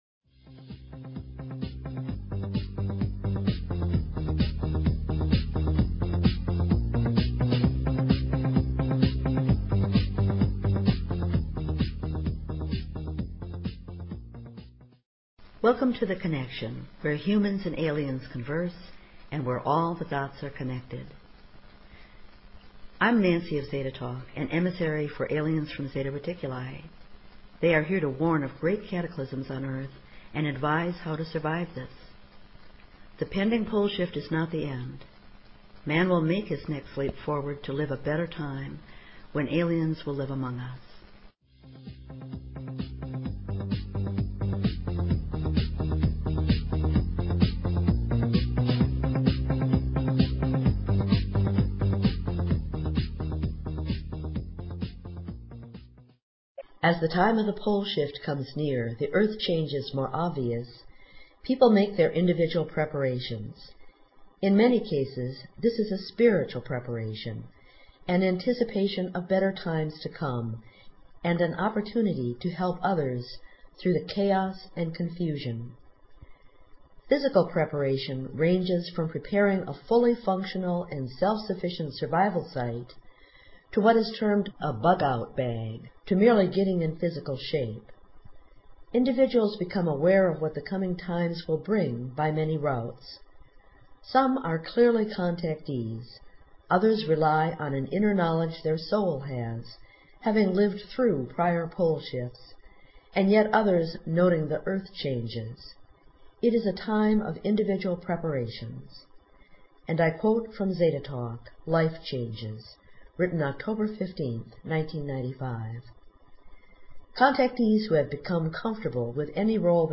Talk Show Episode, Audio Podcast, The_Connection and Courtesy of BBS Radio on , show guests , about , categorized as